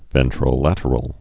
(vĕntrō-lătər-əl)